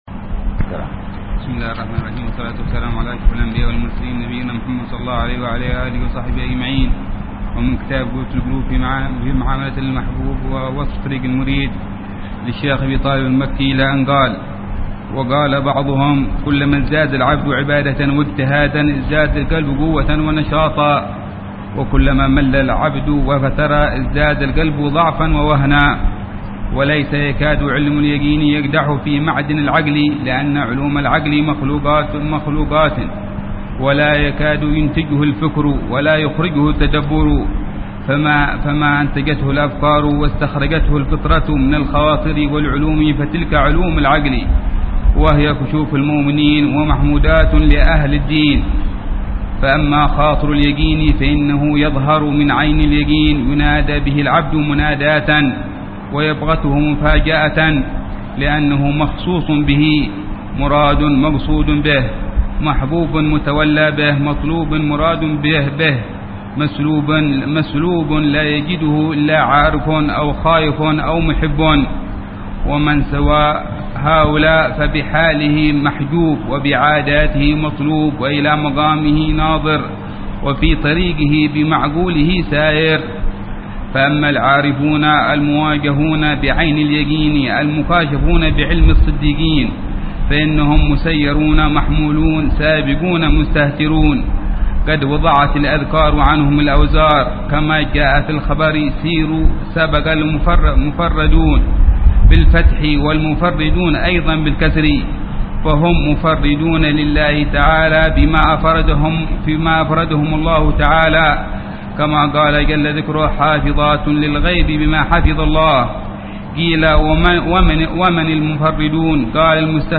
قراءة بتأمل وشرح لمعاني كتاب قوت القلوب للشيخ: أبي طالب المكي ضمن دروس الدورة التعليمية السادسة عشرة بدار المصطفى 1431هجرية.